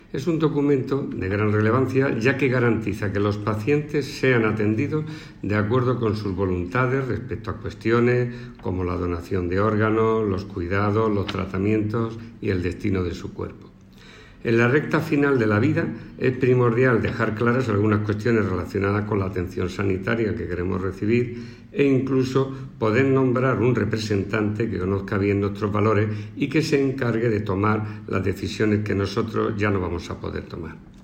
Declaraciones del  director general de Planificación, Farmacia e Investigación, Jesús Cañavate, sobre el Registro de Instrucciones Previas.